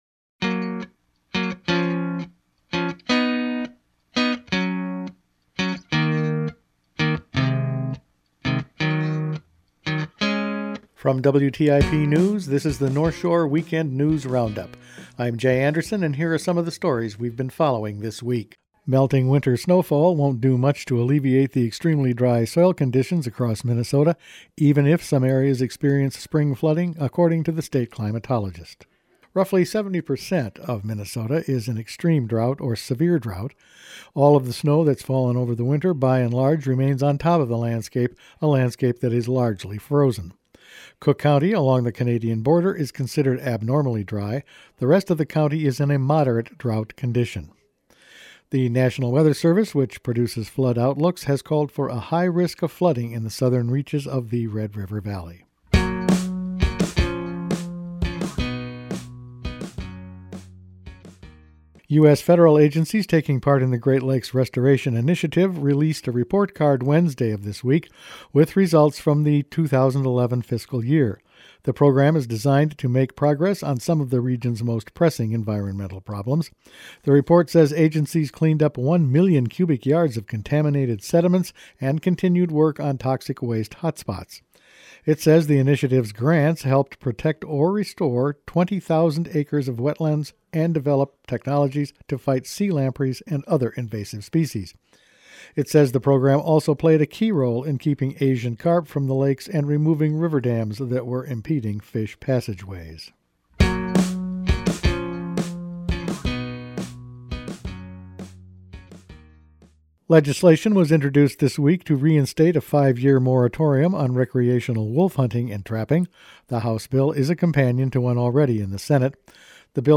Weekend News Roundup for February 9
Each weekend WTIP news produces a round up of the news stories they’ve been following this week. Dry conditions continue despite the snow, a wolf hunt moratorium is proposed, the state will stop using products containing an environment-harming chemical, and a big land owner might start restricting public access …all in this week’s news.